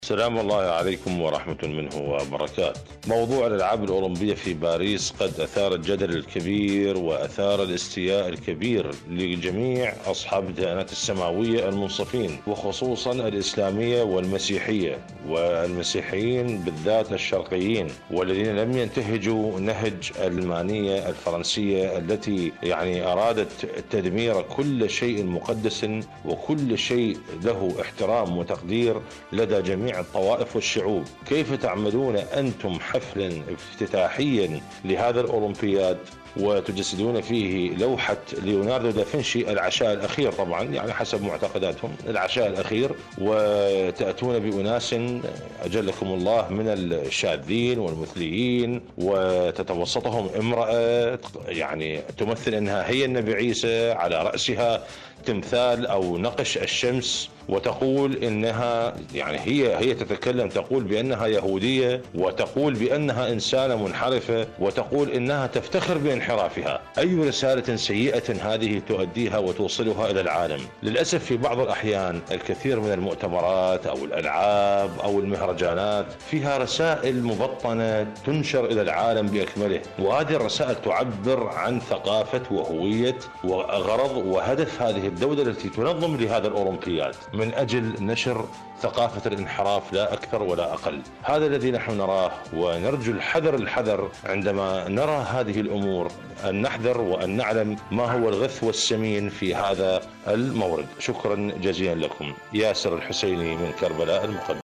مشاركة صوتية